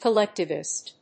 /kʌˈlɛktɪvɪst(米国英語), kʌˈlektɪvɪst(英国英語)/